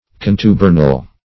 Contubernal \Con*tu"ber*nal\ (k[o^]n*t[=u]"b[~e]r*nal),